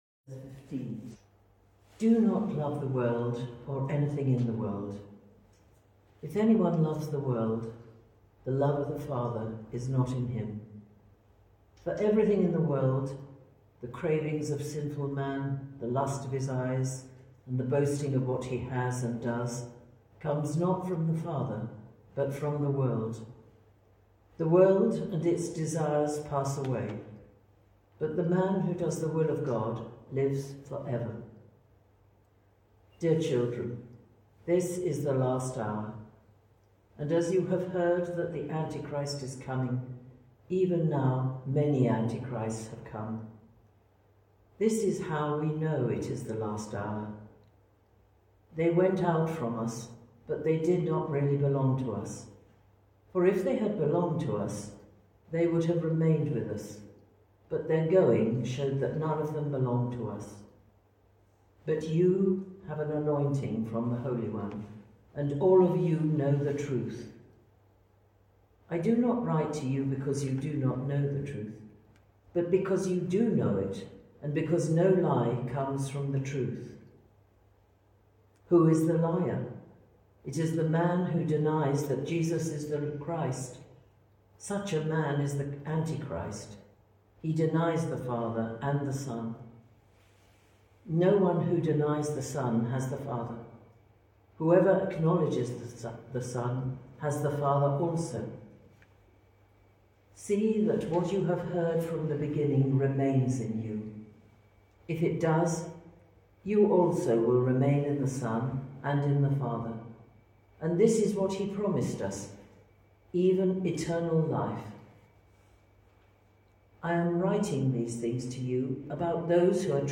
1 John Passage: 1 John 2:15-27 Service Type: Thursday 9.30am Topics